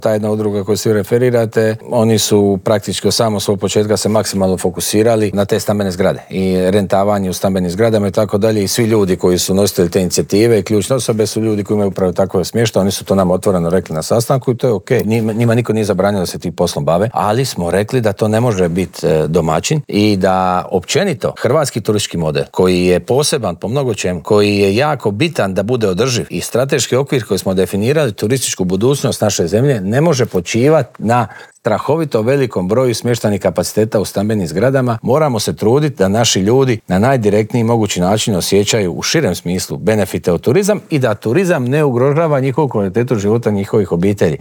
O dosad ostvarenim rezultatima, očekivanjima od ljetne turističke sezone, ali i o cijenama smještaja te ugostiteljskih usluga razgovarali smo u Intervjuu tjedna Media servisa s ministriom turizma i sporta Tončijem Glavinom.